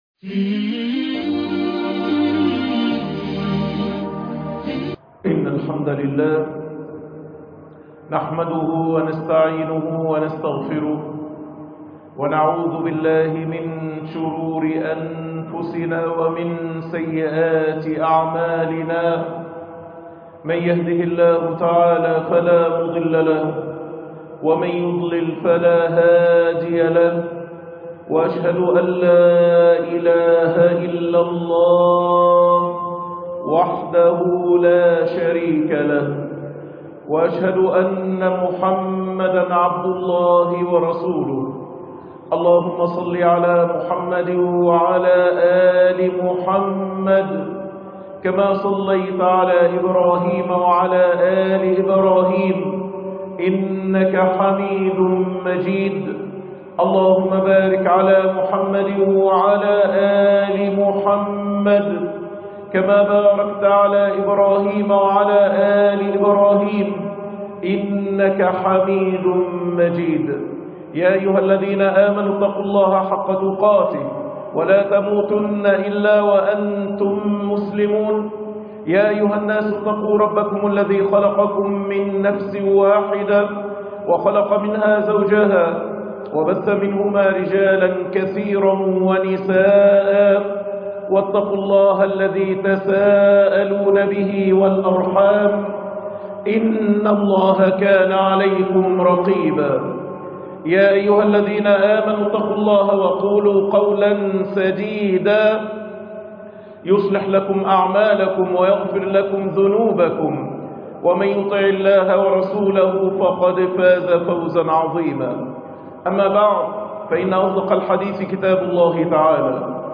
حين صرنا وليمة للذئاب خطبة مؤثرة ومهمة